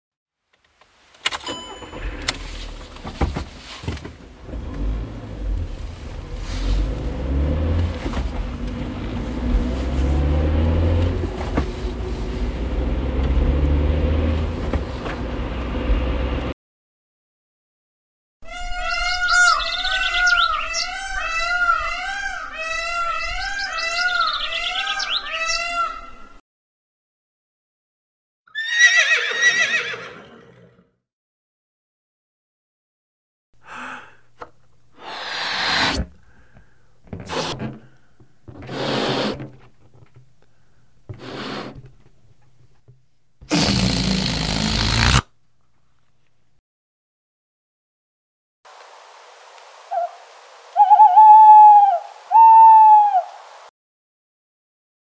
Geräusche-Quiz
Mit einem Aufnahmegerät bewaffnet gehen die Kids auf
Geräuschejagd und fangen jede Menge Sounds ein.